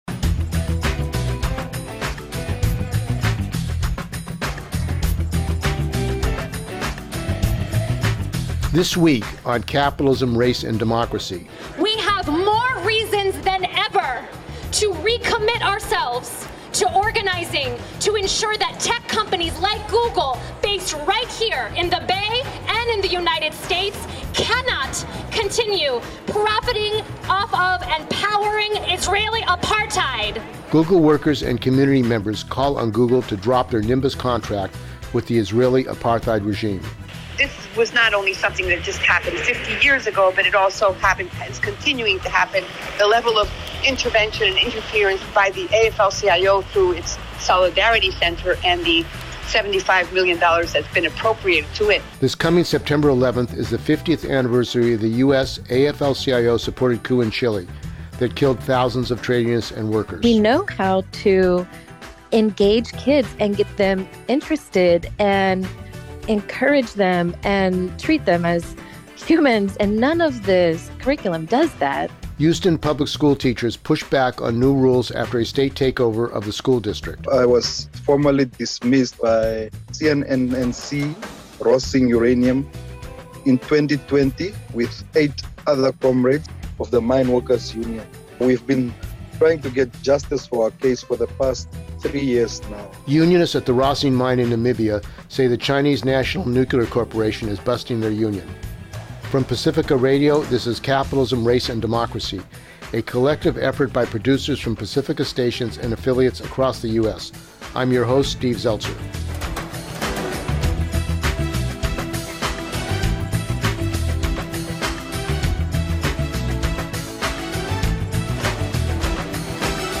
From Pacifica Radio, this is Capitalism, Race, & Democracy, a collective effort by producers from Pacifica stations and affiliates across the US.